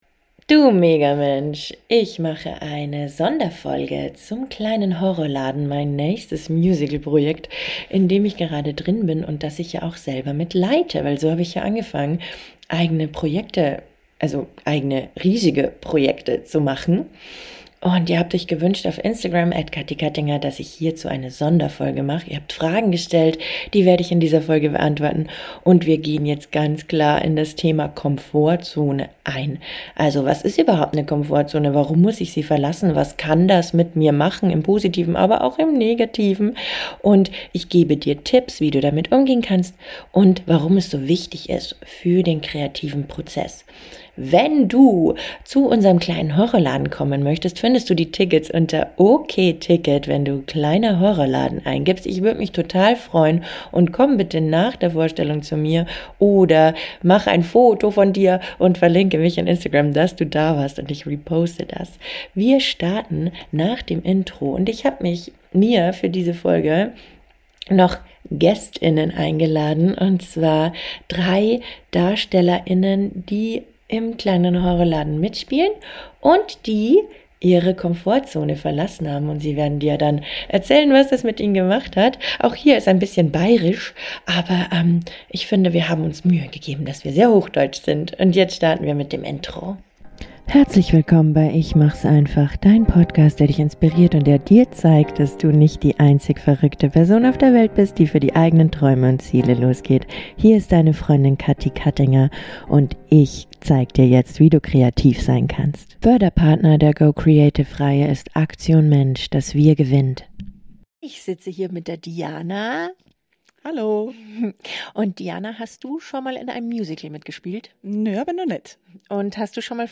In der heutigen Folge geht es darum, warum wir so gern in unserer Komfortzone bleiben und warum es so wichtig ist, diese zu verlassen, um kreativ und persönlich zu wachsen. Ich spreche darüber, wie das Verlassen der Komfortzone unser Leben verändern kann und welche Phasen wir dabei durchlaufen. Außerdem habe ich die Darsteller*innen aus meinem aktuellen Musical Der Kleine Horrorladen gefragt, wie es für sie war, ihre Komfortzone zu verlassen und wie sie daran gewachsen sind.